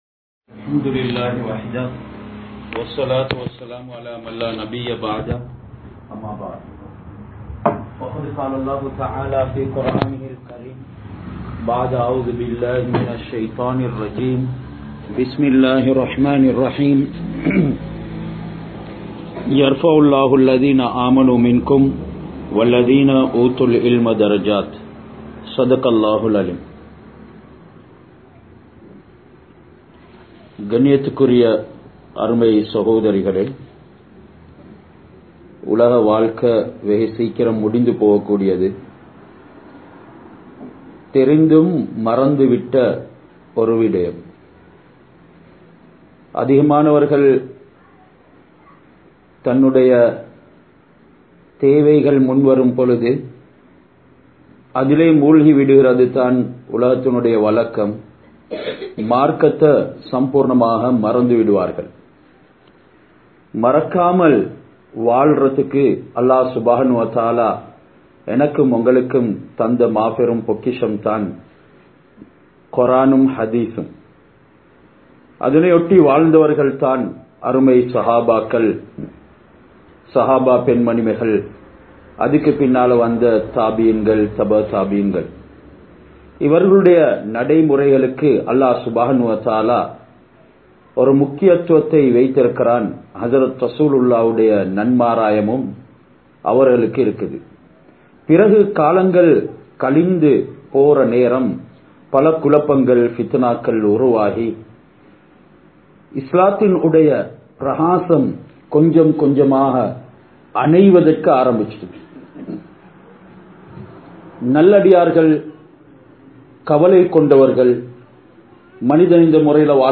Maarka Arivulla Pennin Panpuhal (மார்க்க அறிவுள்ள பெண்ணின் பண்புகள்) | Audio Bayans | All Ceylon Muslim Youth Community | Addalaichenai